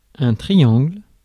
Ääntäminen
Synonyymit trigon love triangle menage à trois Ääntäminen US : IPA : [ˈtʃɹaɪ.ˌæŋ.ɡl̩] Tuntematon aksentti: IPA : /ˈtraɪˌæŋgəl/ IPA : /ˈtɹaɪ.ˌæŋ.ɡl̩/ IPA : /ˈtraɪ.æŋ.ɡəl/ Lyhenteet ja supistumat (musiikki) Trgl.